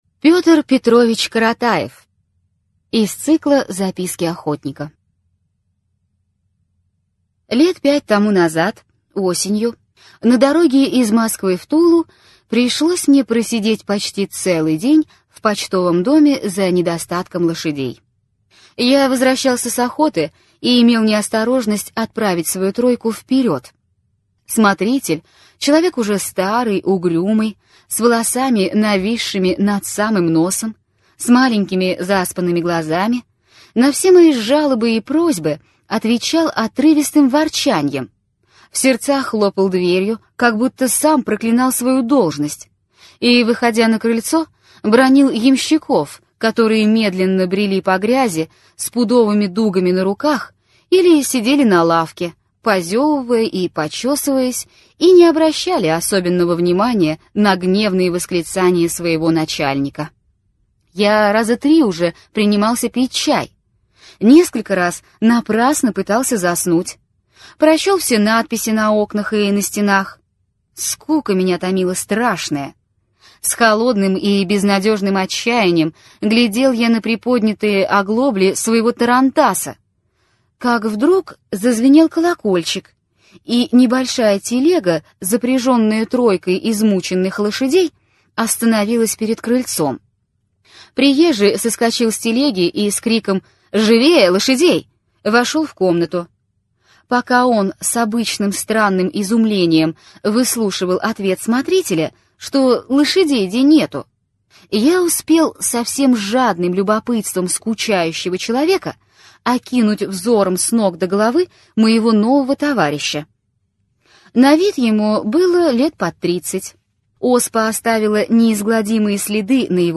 Аудиокнига Из записок охотника: Гамлет Щигровского уезда. Хорь и Калиныч. Петр Петрович Каратаев | Библиотека аудиокниг